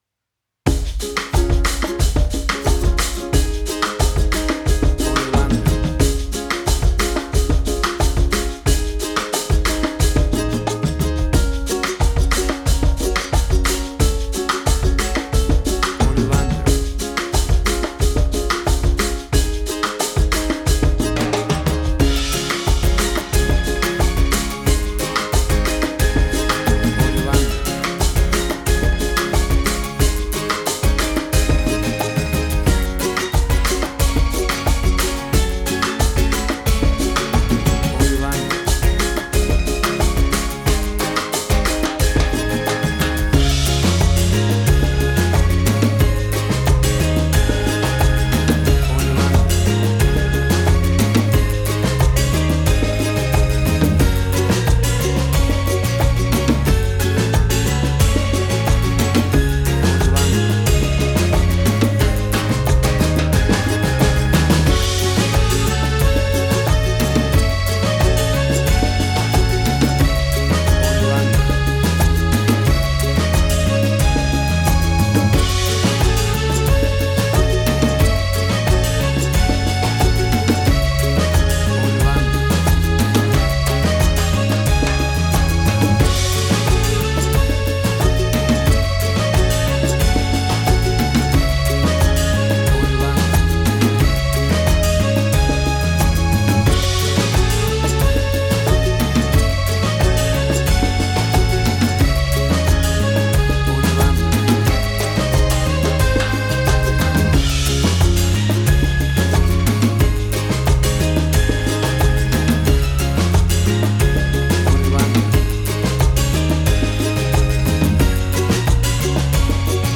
Tempo (BPM): 90